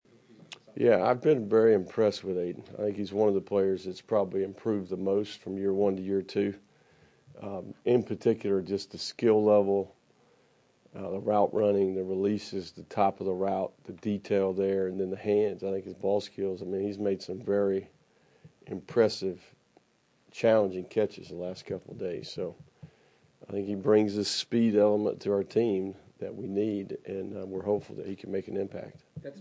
Florida football coach Billy Napier previewed the Week 1 matchup against No. 19 Miami during a news conference Monday.